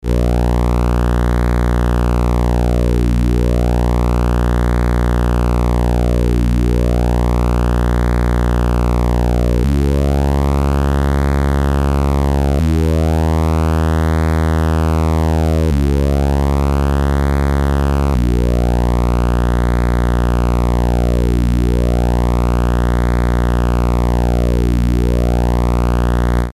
A lowpass filter was realised with this concept, implemented tuning range being 250Hz-5kHz.
Sound example of the voltage controlled filter
"LeftFilterOut-RightFilterIn" is a demonstration of the filter sweeping with a simple static sawtooth as input wave and a control voltage triangular wave at 0.4Hz from 0V to 5V . The left channel gives the resulting filter output, the right channel gives the clean input signal for reference.
filtchoke-left-out-right-in.mp3